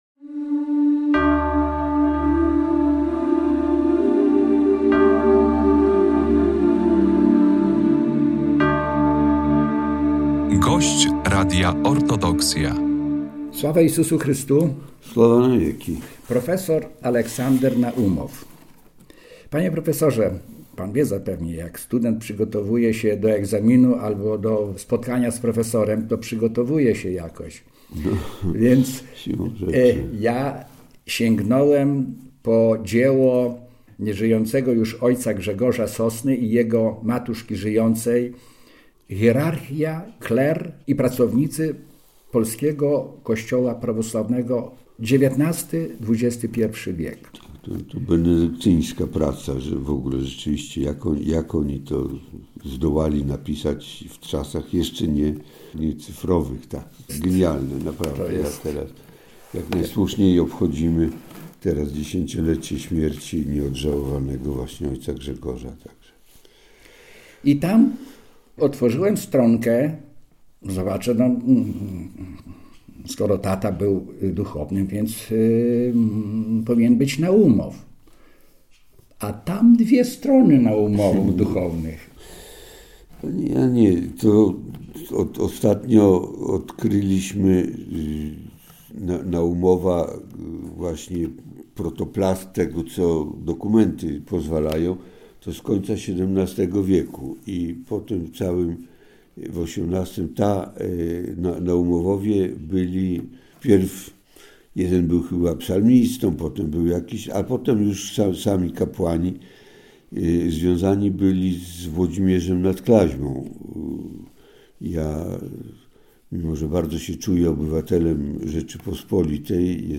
A kiedyś to było… rozmowy o życiu i Cerkwi – to cykl audycji radiowych z osobami, którzy tworzyli historię Polskiego Autokefalicznego Kościoła Prawosławnego. W każdym odcinku programu zaproszeni goście opowiadają o swoich wspomnieniach związanych z pracą na rzecz Cerkwi na przestrzeni ostatnich kilkudziesięciu lat.